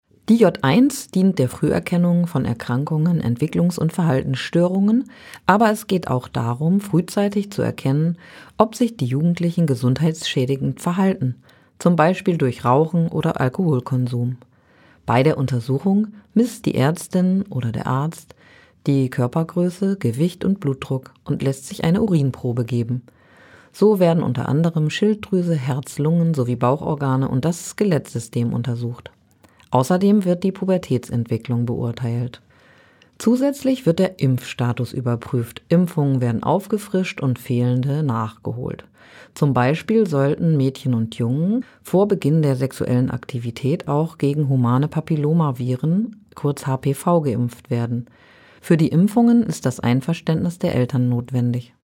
O-Töne25.05.2023